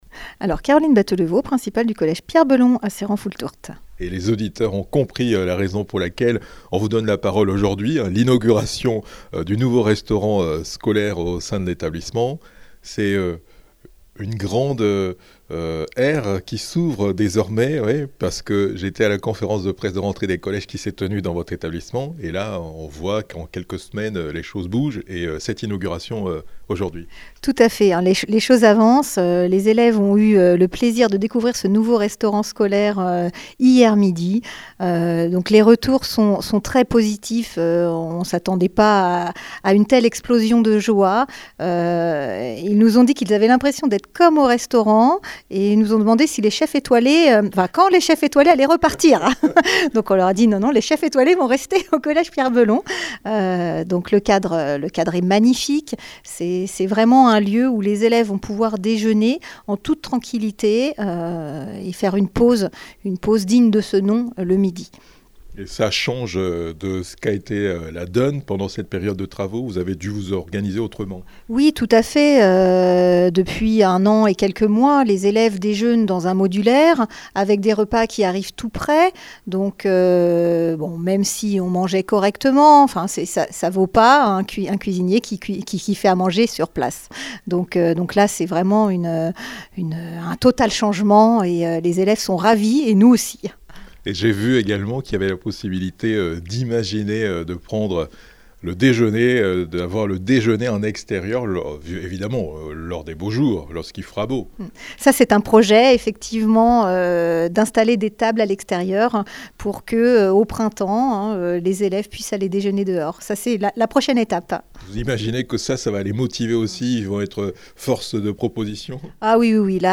Inauguration restaurant scolaire collège PB